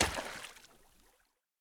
shallow-water-03.ogg